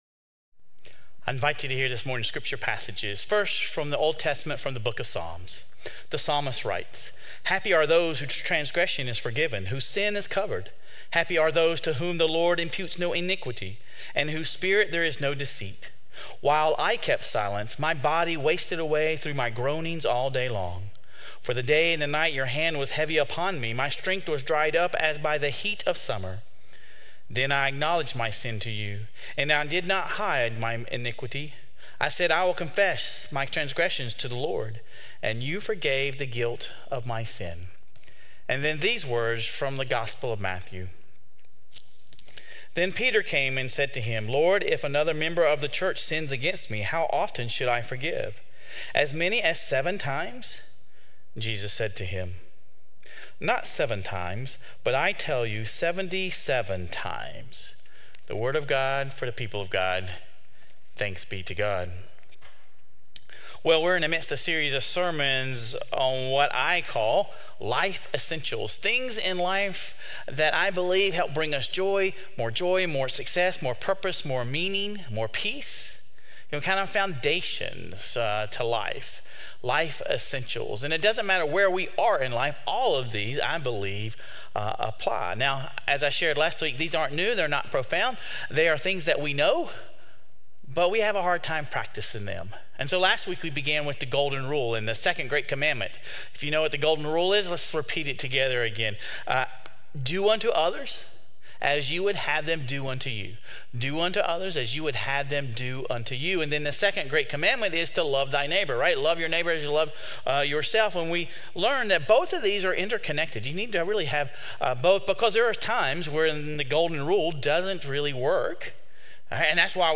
This week, we explore Forgiveness. Sermon Reflections: How does the scripture from the Book of Psalms set the tone for the sermon on forgiveness?